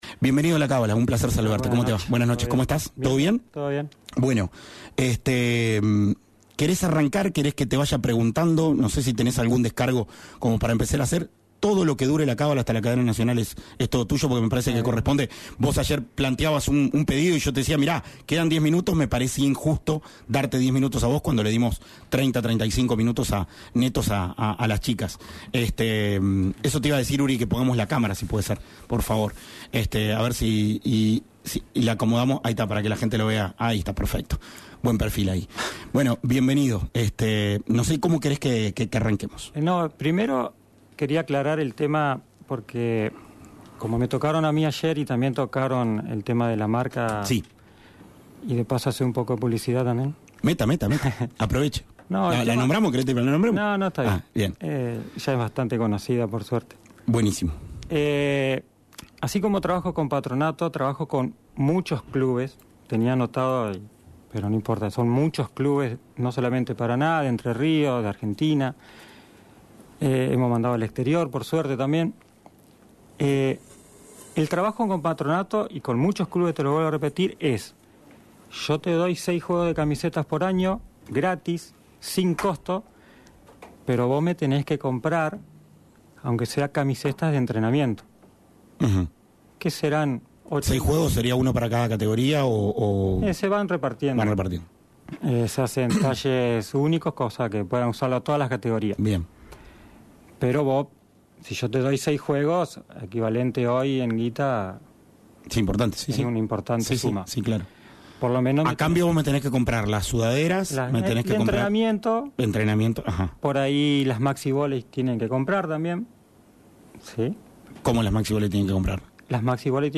en los estudios de Radio Uner Paraná